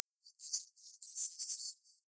тест-файл ЧМ, несущая 12 кГц, дискрет 48 кГц